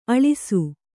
♪ aḷisu